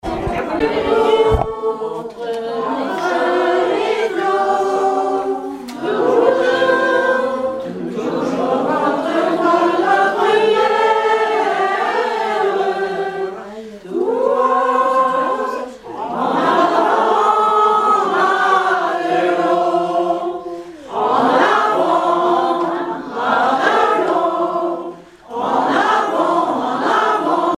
Informateur(s) Club d'anciens de Saint-Pierre association
circonstance : cantique ; circonstance : maritimes ;
Genre strophique
Collecte de chansons